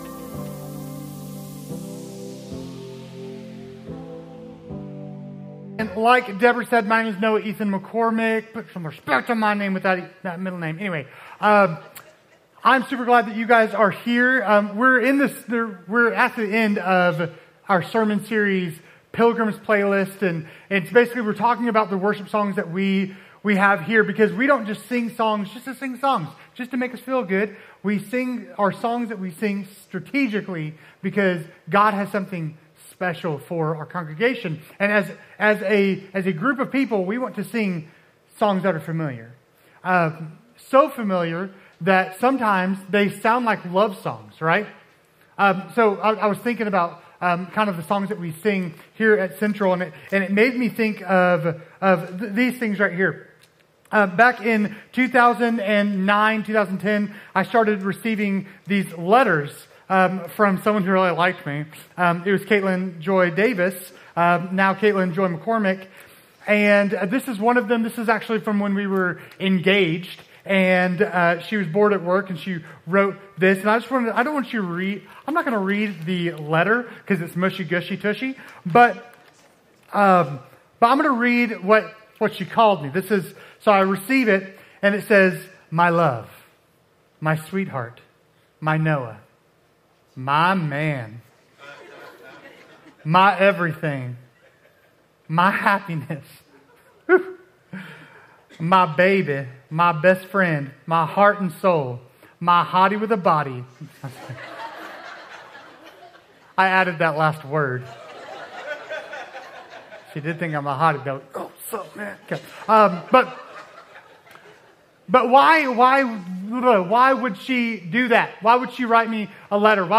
Our final sermon in the sermon series "Pilgrim's Playlist" - "Great Are You Lord"